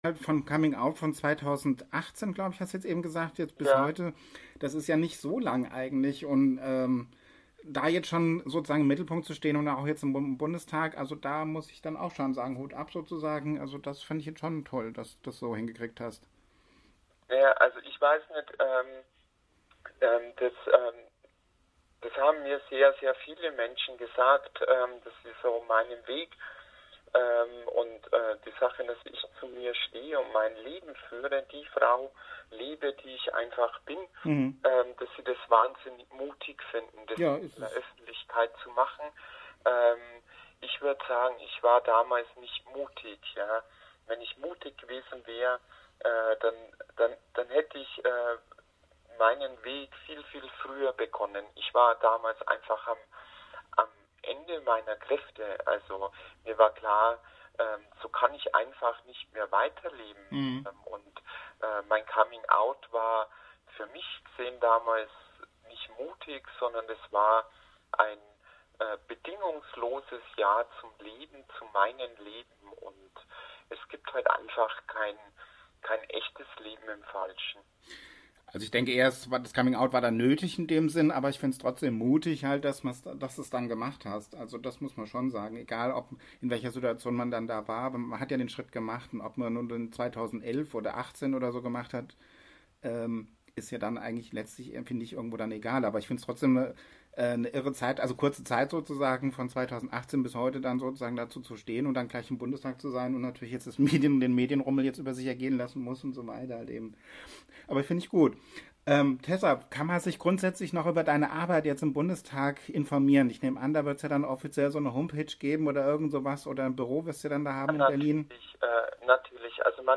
Wir sprechen mit Tessa Ganserer